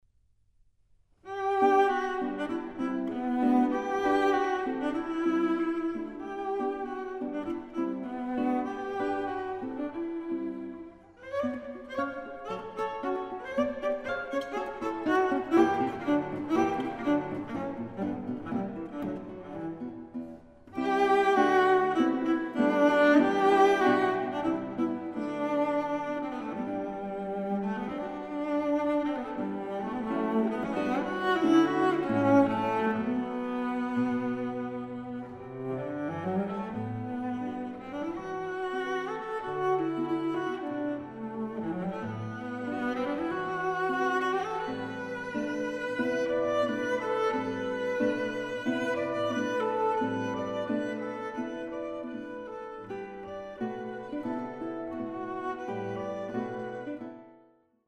classical guitar
cello